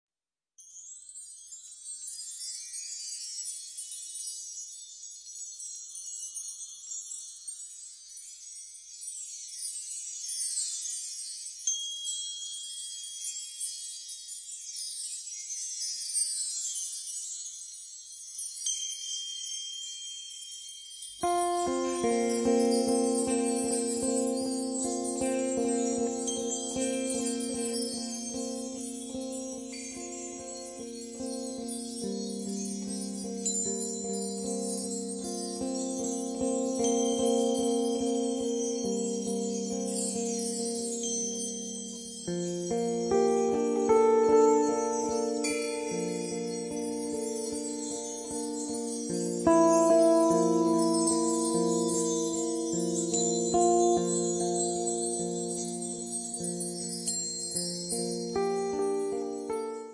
sax tenore e soprano, live electronics
chitarra
contrabbasso
batteria e percussioni
sinuosa ballad